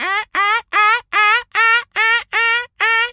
seagul_squawk_seq_01.wav